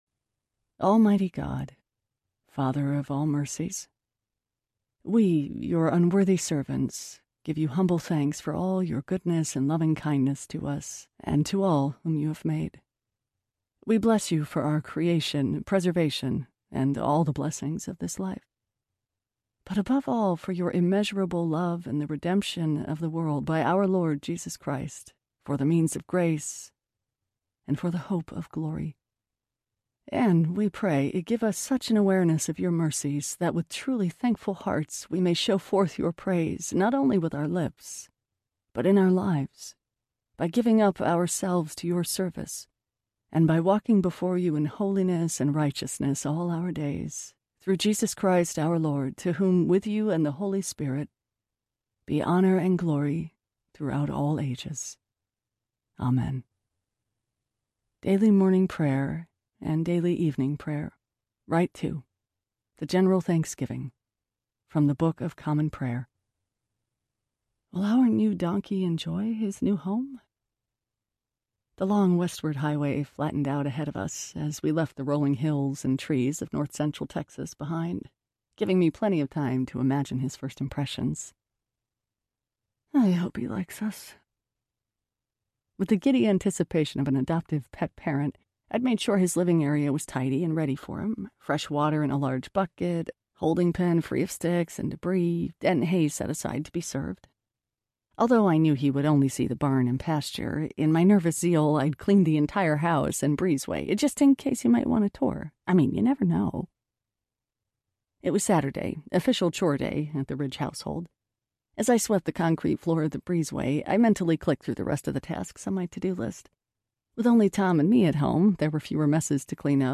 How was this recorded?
5.7 Hrs. – Unabridged